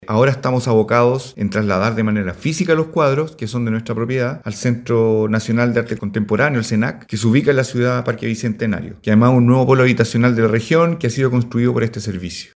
Mientras que desde el Serviu Metropolitano, su director Roberto Acosta, sostuvo que el organismo está preocupado, ahora, de trasladar los cuadros desde las dependencias de República 475 hasta el Centro Nacional de Arte Contemporáneo.